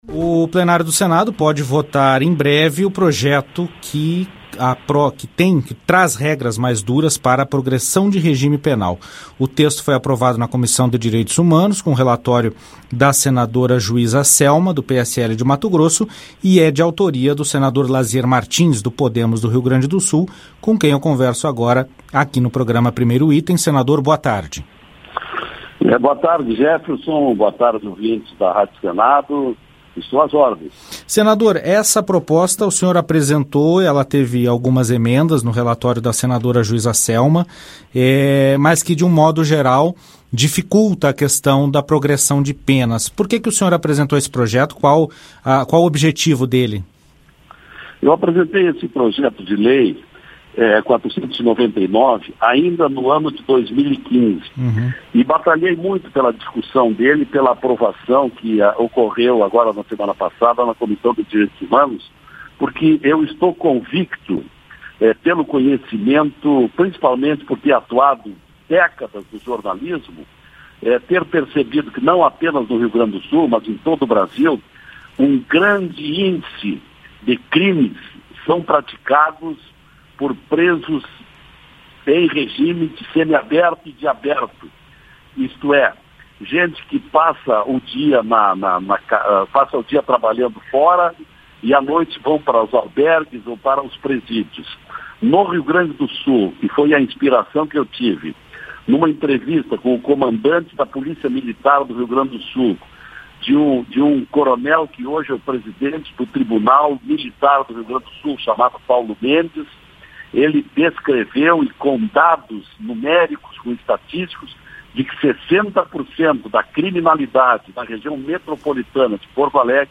Senador Lasier Martins